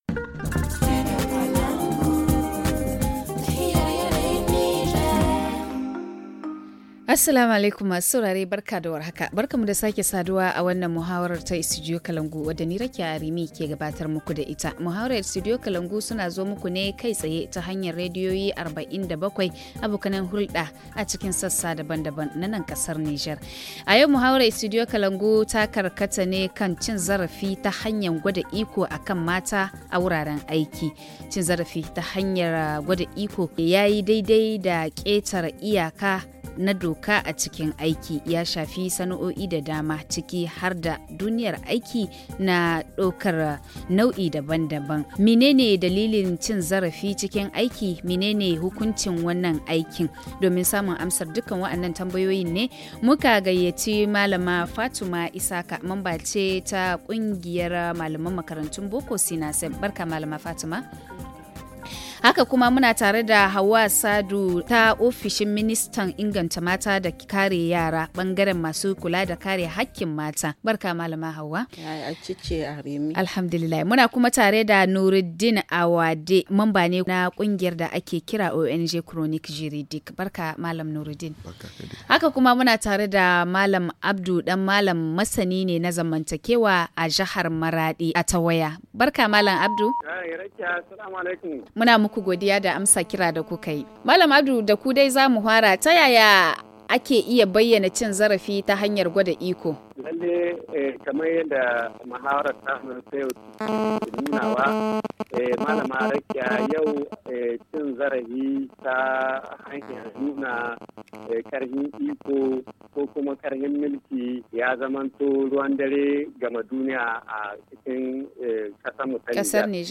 HA Le forum en haoussa https